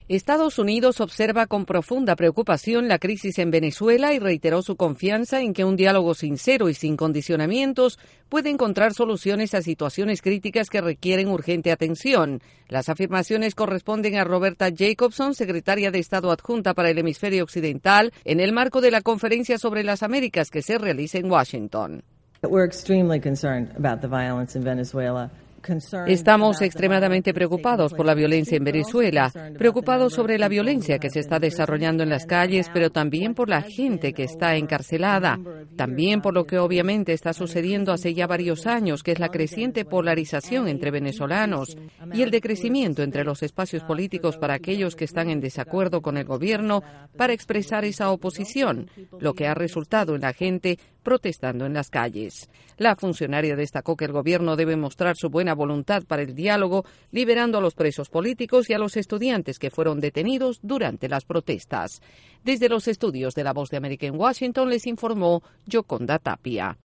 Una alta funcionaria del Departamento de Estado reiteró hoy la preocupación de Estados Unidos por la crisis en Venezuela, en particular por los presos políticos y la violación de los derechos humanos. Desde la Voz de América en Washington DC informa